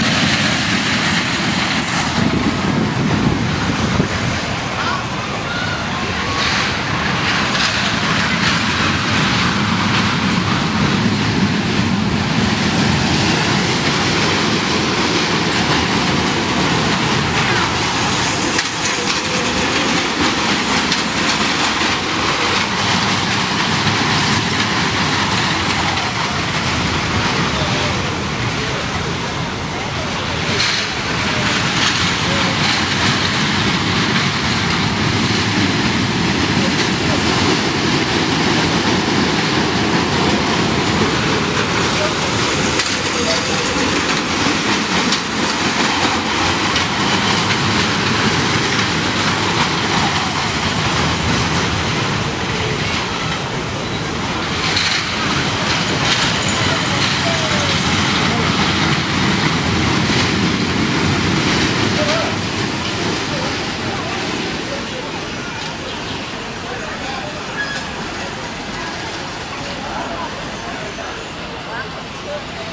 过山车
描述：此录音是在圣彼得堡游乐园进行的。录音机：Tascam DR100MKIII
标签： 公平 娱乐 游乐项目 过山车 乐趣
声道立体声